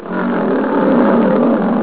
All lions are very vocal and use a snarls and growls to communicate.
Lion Growling
lion-10-sounds.wav